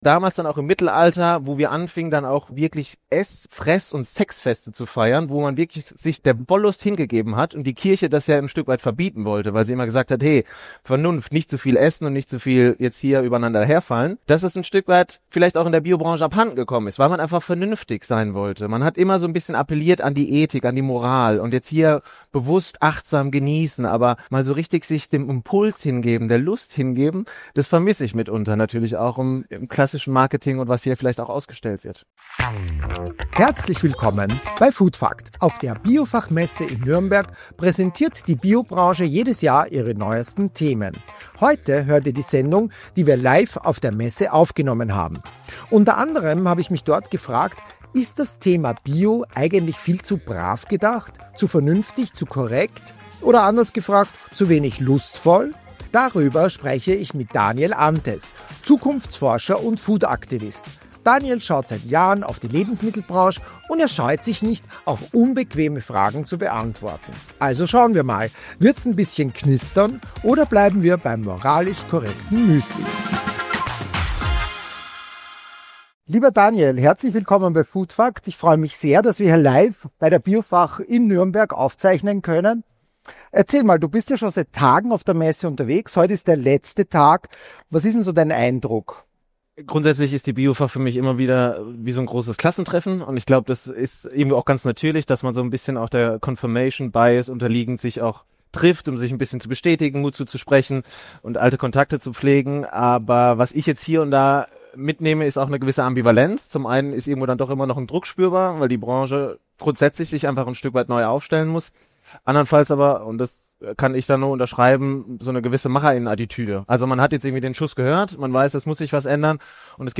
Live von der Biofach in Nürnberg